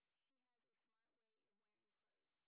sp26_white_snr20.wav